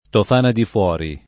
tof#na di fU0ri]; insieme, le Tofane [tof#ne]